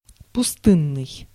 Ääntäminen
IPA : /dɪˈzɛː(ɹ)təd/